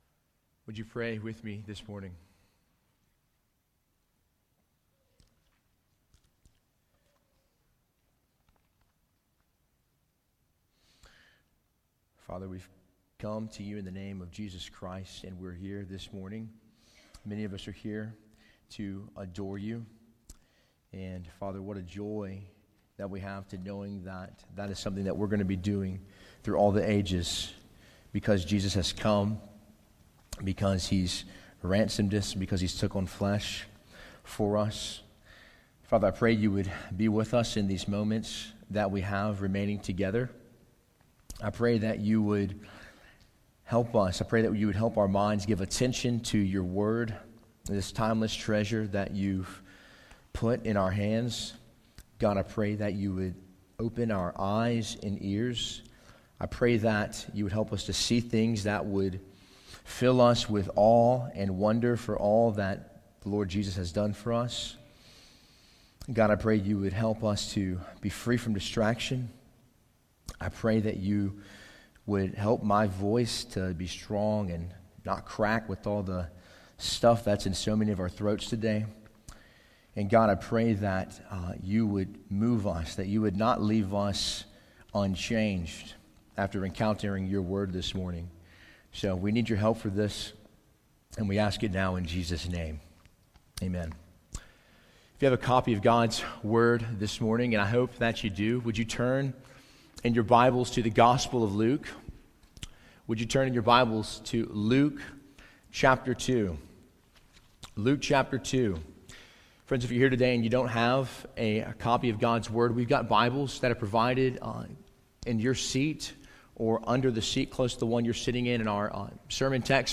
Sermon Audio 2019 December 1